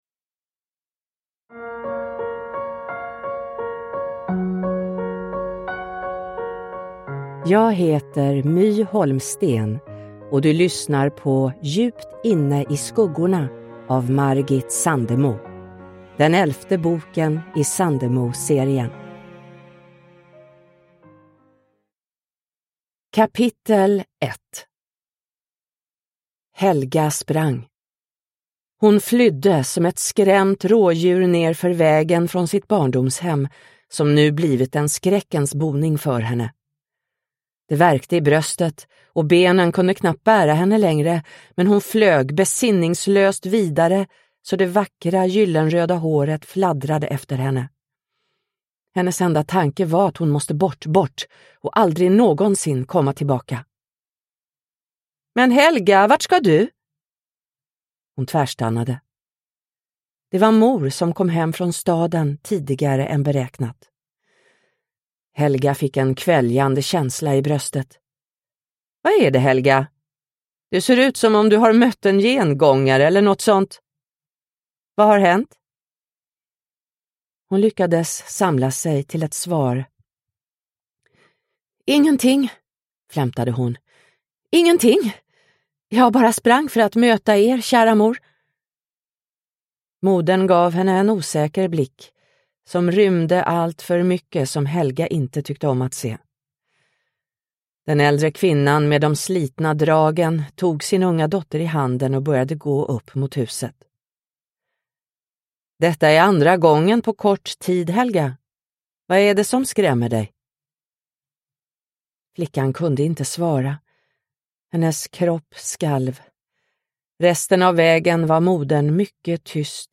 Djupt inne i skuggorna – Ljudbok – Laddas ner